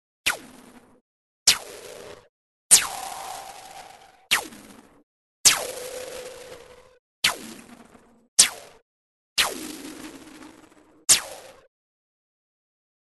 Звуки бенгальских огней
Сборник звуков зажигания бенгальских огней для видеомонтажа